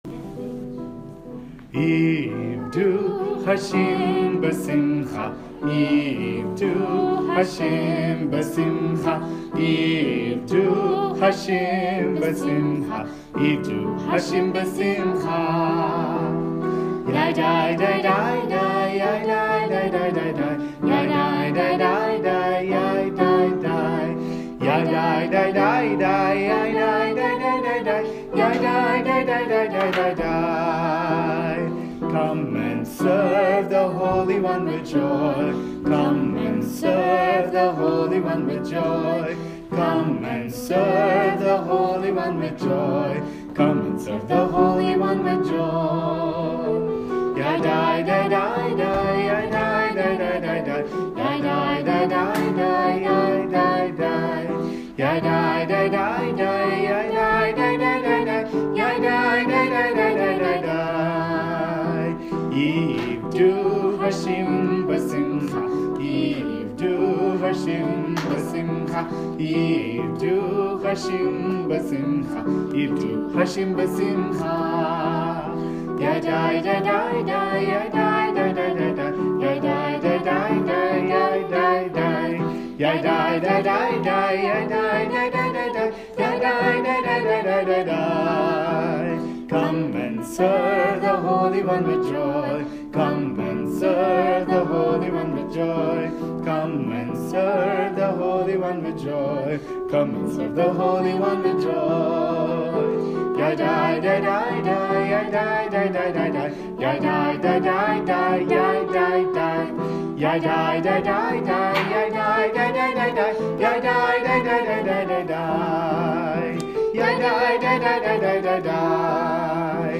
Devotional Songs
Minor (Natabhairavi)
8 Beat / Keherwa / Adi
Fast
6 Pancham / A
3 Pancham / E
Lowest Note: m1 / F (lower octave)
Highest Note: D1 / G#